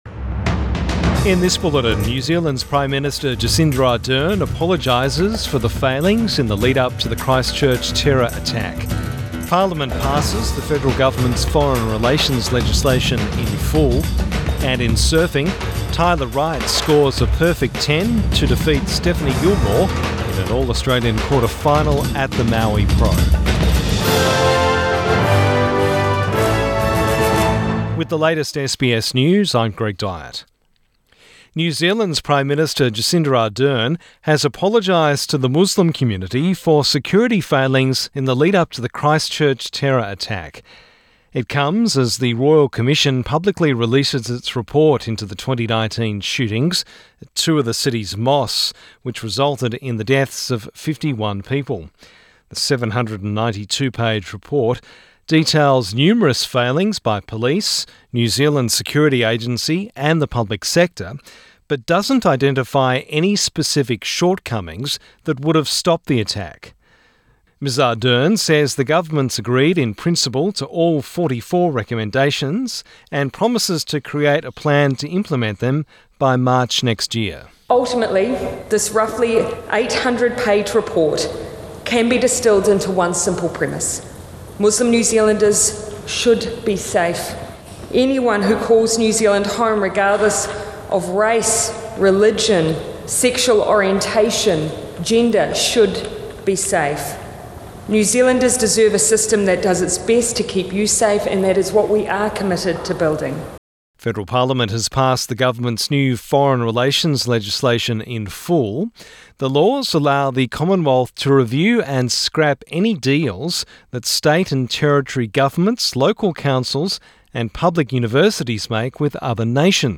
PM bulletin 8 December 2020